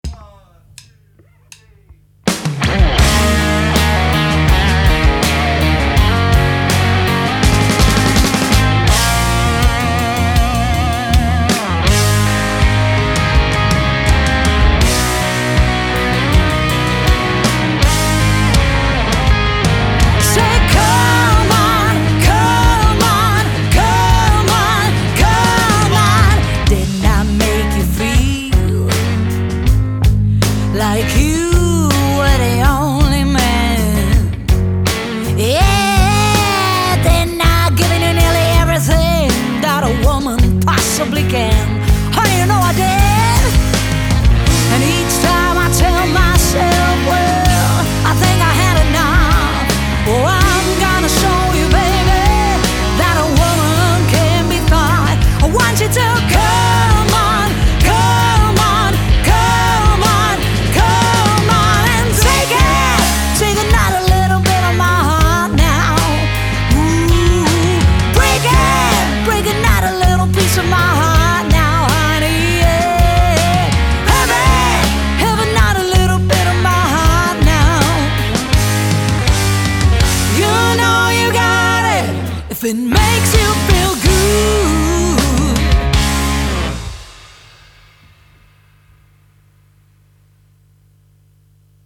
From Country Classics to Full-Throttle Party-Rock Anthems